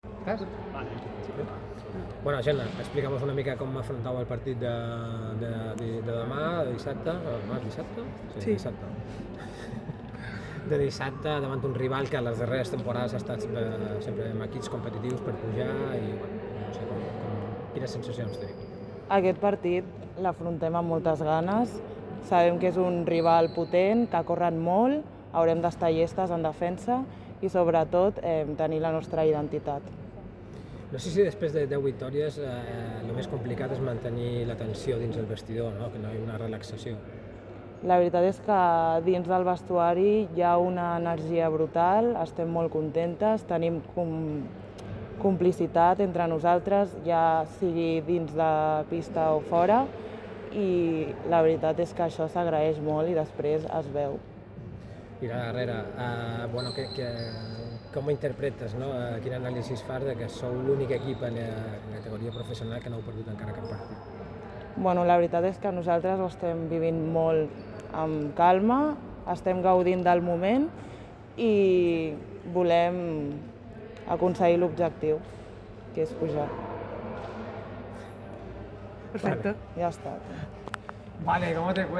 El club y la compañía han formalizado el acuerdo en la rueda de prensa previa al encuentro.